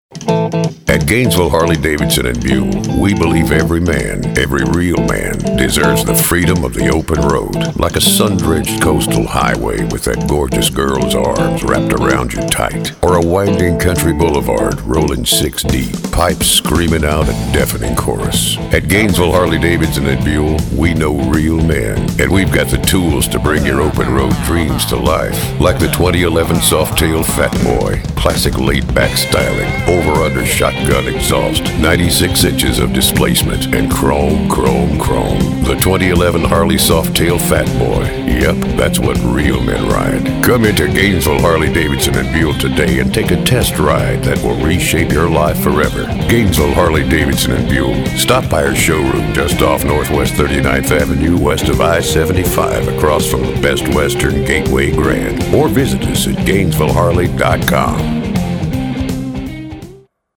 We were eager to work with the Harley-Davidson brand, so we spun up this little spec radio ad for the local dealer.
GNV-Harley-radio-spec.mp3